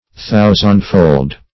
Thousandfold \Thou"sand*fold`\